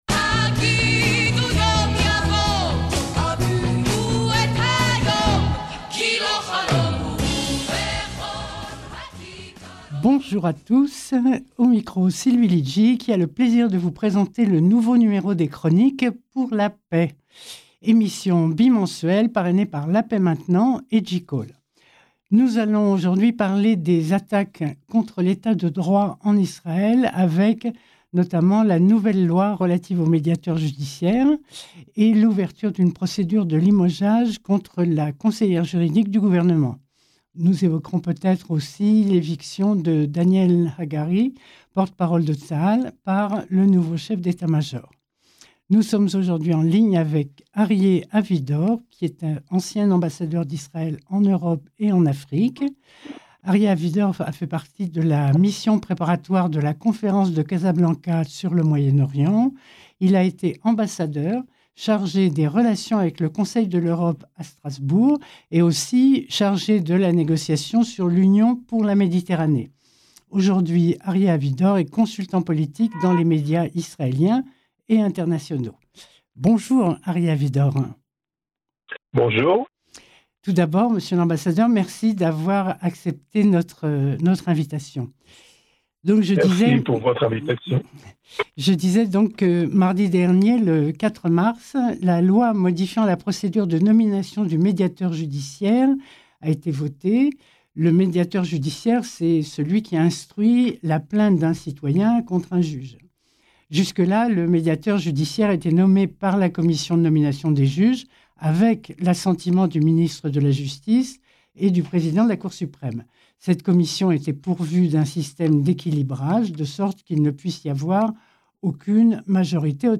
Chroniques pour la Paix, émission bimensuelle de Radio Shalom